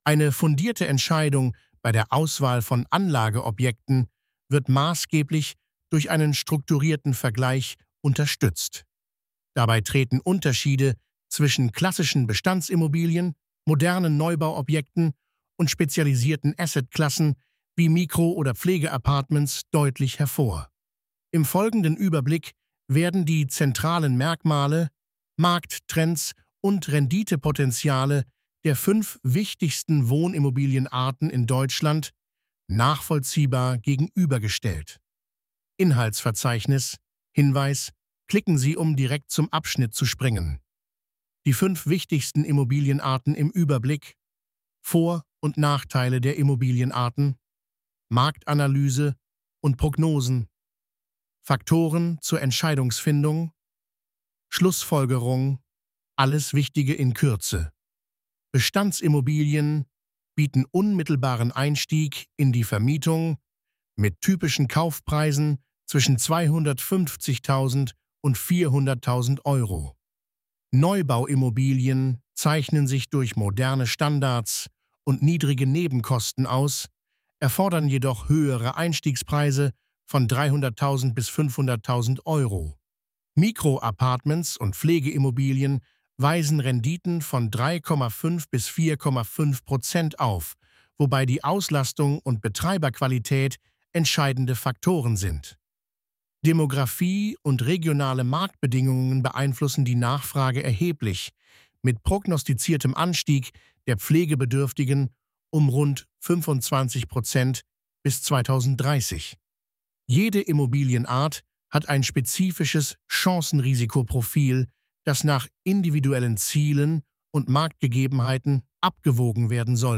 Lassen Sie sich den Artikel von mir vorlesen.